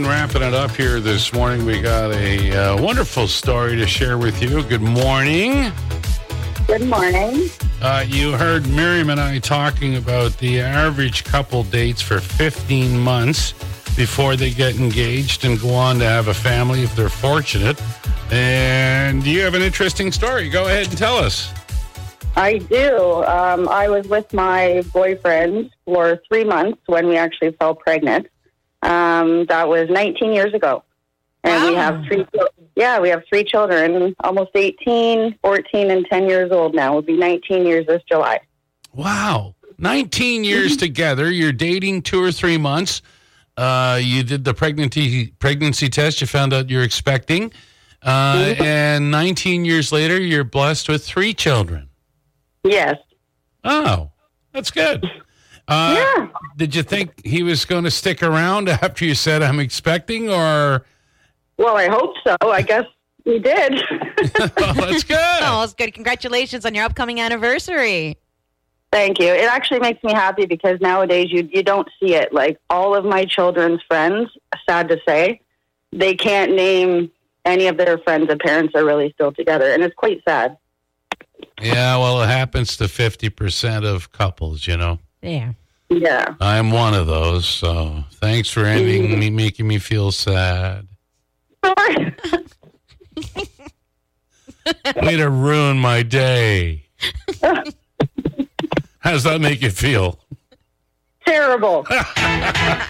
LISTEN: Callers share their whirlwind love stories with the MIX Morning Crew